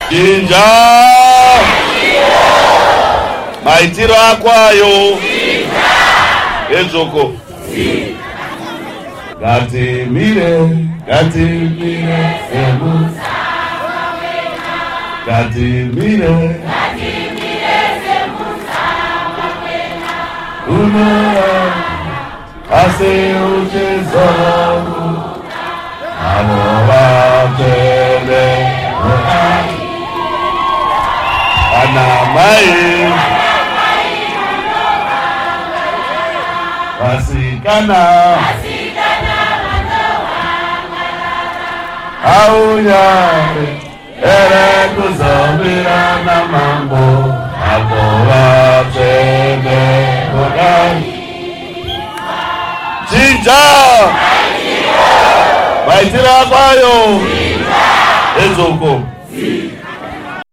Morgan Tsvangirai Rally Song